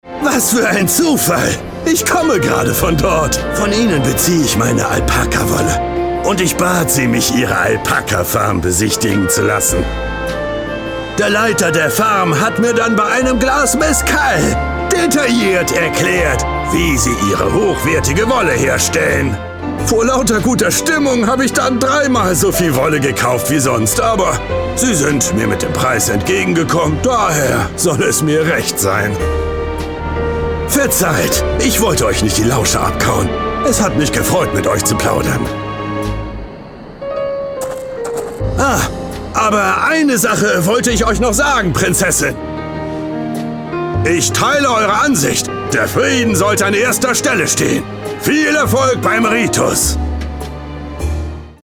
Videojuegos
Cabina de voz: Desone
Micrófono: Austrian Audio OC 18
Preamplificador: golden age pre 73 mk iii